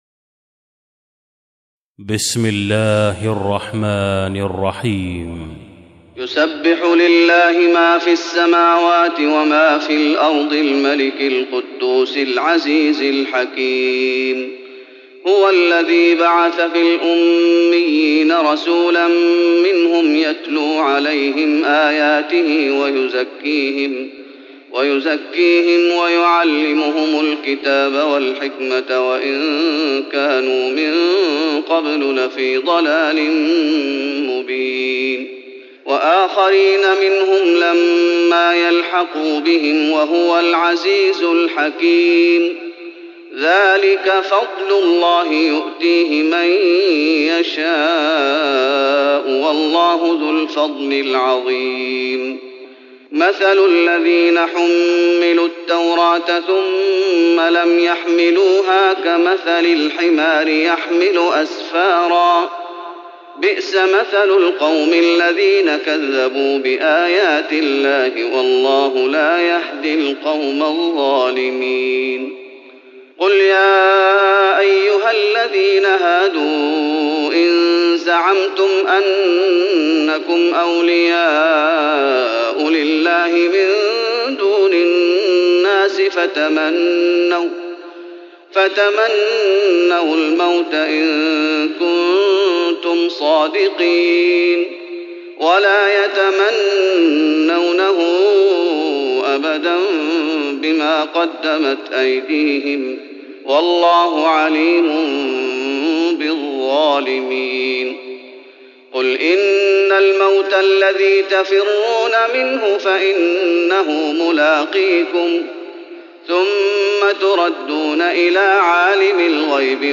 تراويح رمضان 1415هـ من سور الجمعة و المنافقون و التغابن Taraweeh Ramadan 1415H from Surah Al-Jumu'a to At-Taghaabun > تراويح الشيخ محمد أيوب بالنبوي 1415 🕌 > التراويح - تلاوات الحرمين